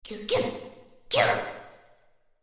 Enemy_Voice_Abyssal_Landing_Imp_Attack.mp3